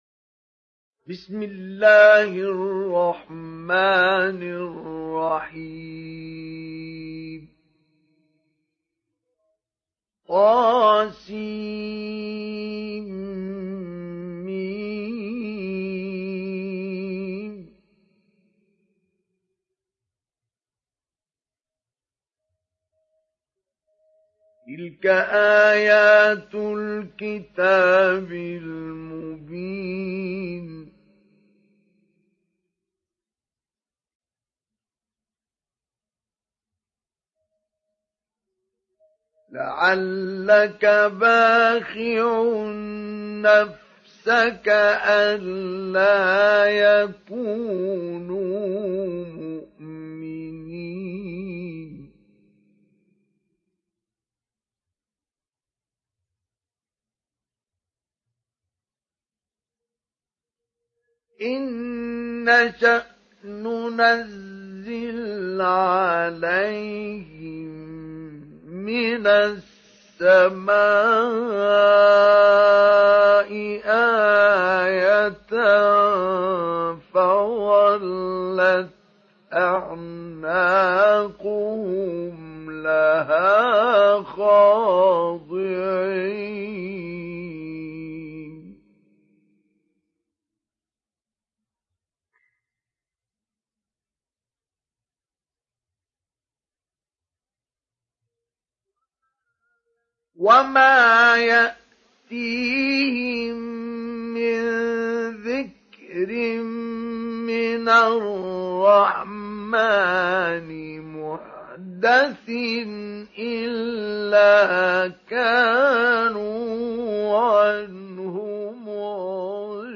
Download Surah Ash Shuara Mustafa Ismail Mujawwad